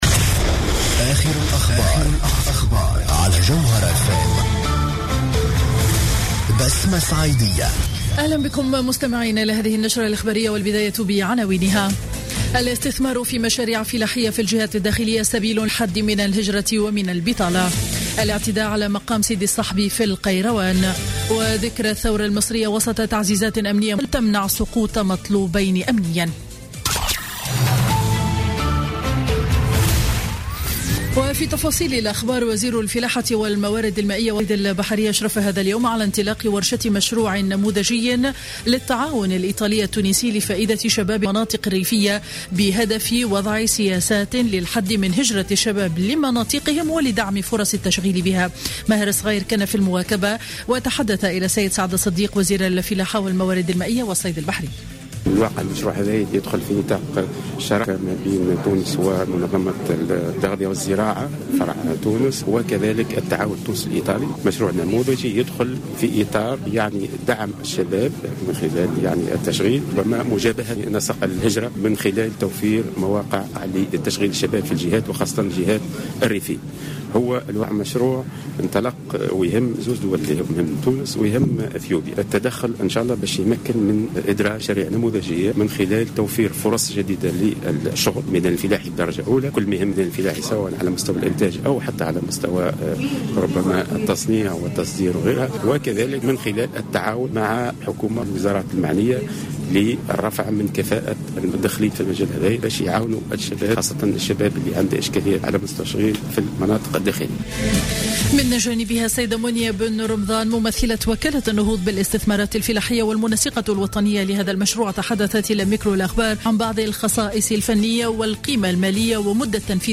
نشرة أخبار منتصف النهار ليوم الاثنين 25 جانفي 2016